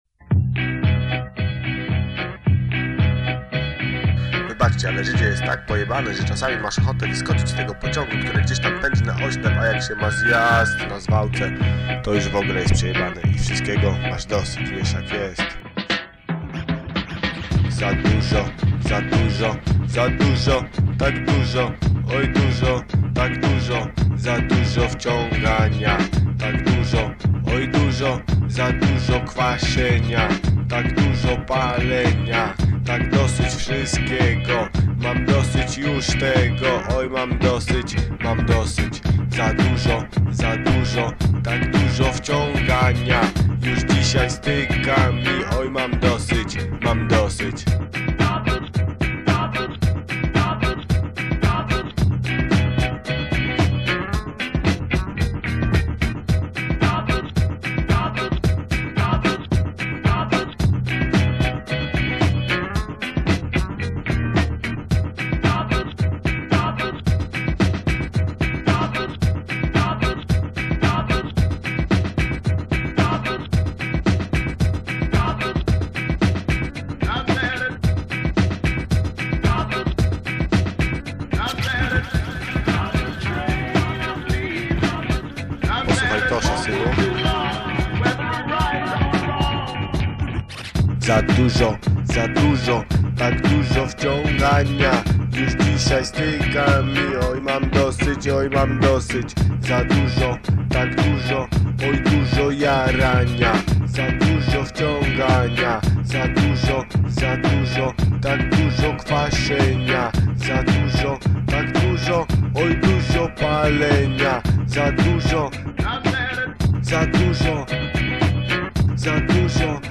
Dub
rmx